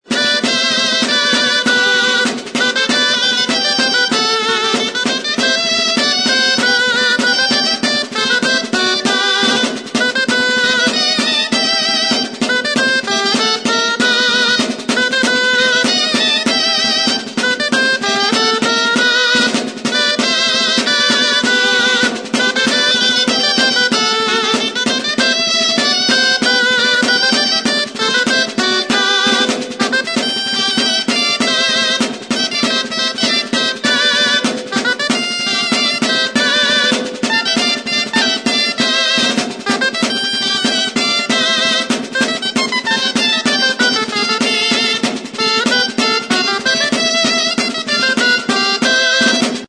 Aerófonos -> Lengüetas -> Doble (oboe)
RIGODON. Iruñeko Gaiteroak.
Mihi bikoitzeko soinu-tresna da.